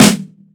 • Acoustic Snare F# Key 315.wav
Royality free acoustic snare sound tuned to the F# note. Loudest frequency: 1802Hz
acoustic-snare-f-sharp-key-315-aEd.wav